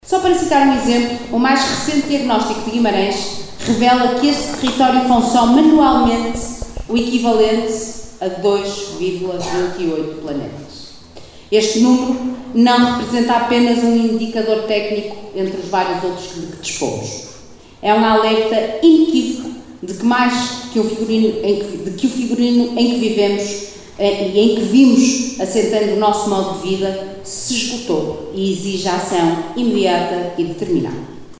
A sessão de abertura contou com uma intervenção da Vereadora Vânia Dias da Silva, que não hesitou em apresentar dados concretos sobre a pegada ecológica do concelho para ilustrar a necessidade de uma mudança de paradigma.